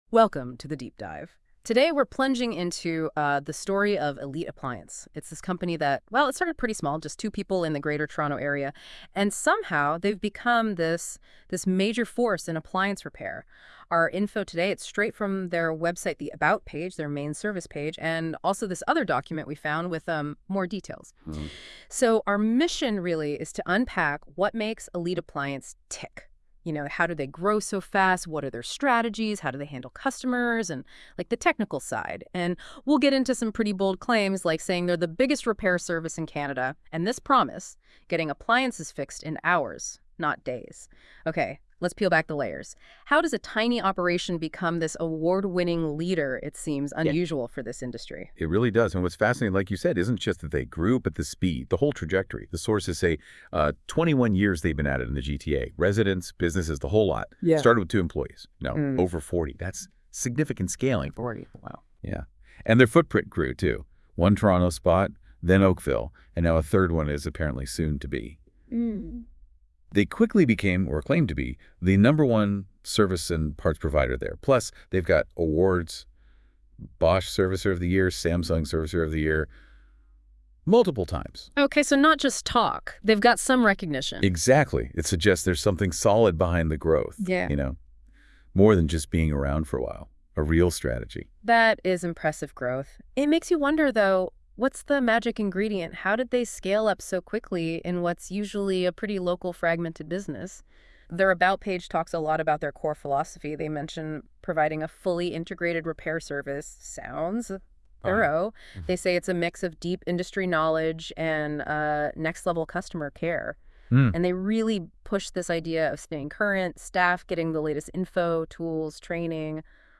EliteApplianceServiceAIPodcast.aac